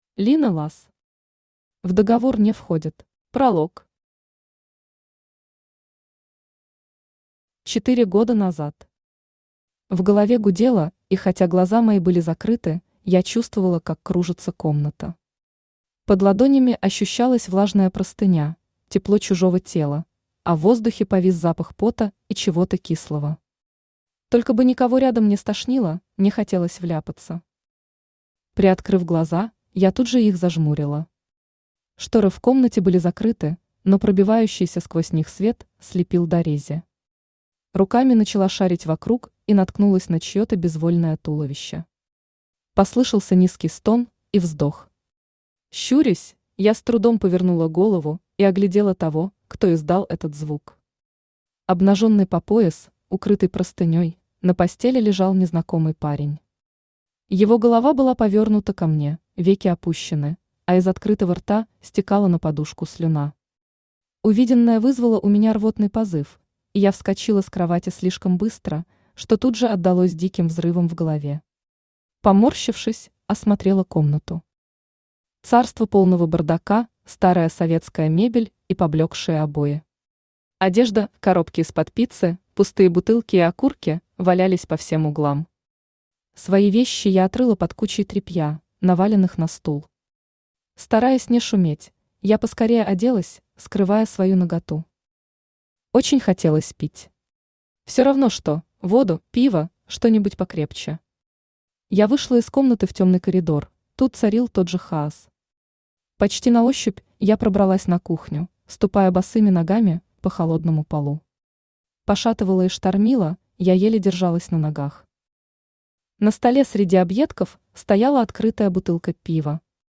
Аудиокнига В договор не входит | Библиотека аудиокниг
Aудиокнига В договор не входит Автор Лина Ласс Читает аудиокнигу Авточтец ЛитРес.